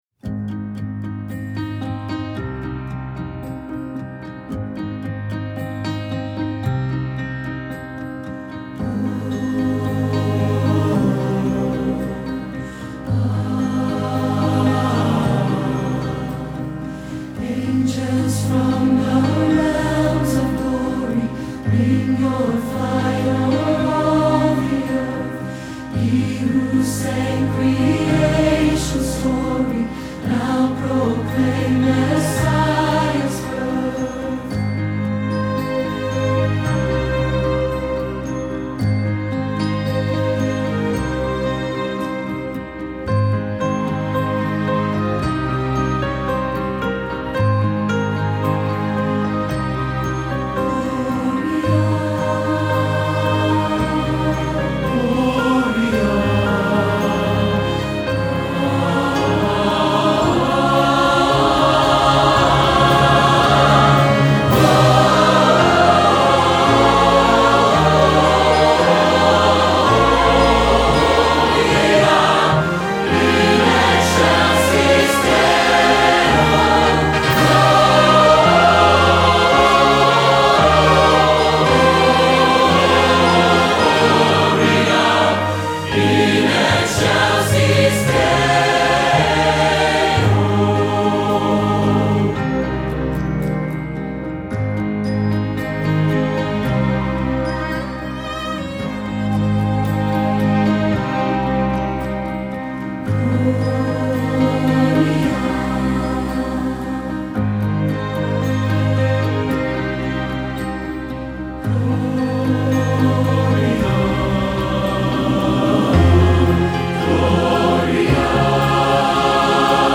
Angels from the Realms of Glory (Choir, Ensemble and Accompaniment track) Here are the individual listening parts for choir: SopranoAltoTenorBass